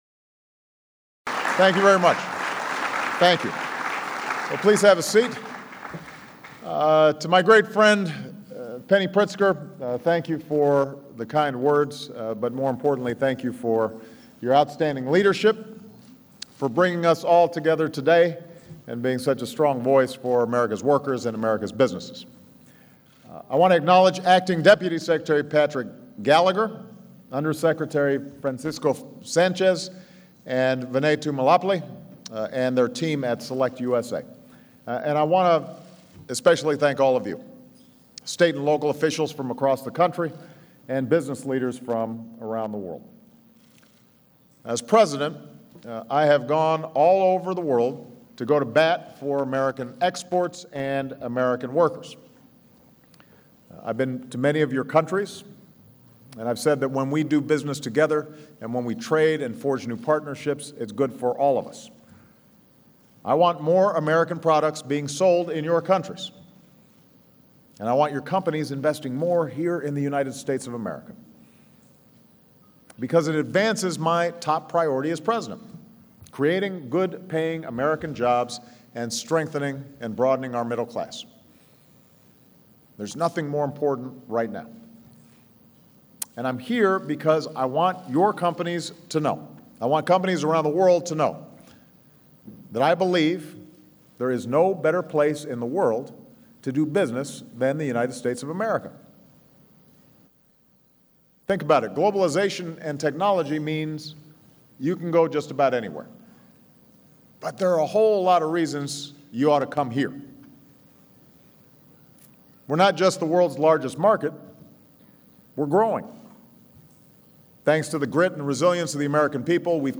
U.S. President Barack Obama tells foreign business leaders that there is no better place in the world to invest and do business than the United States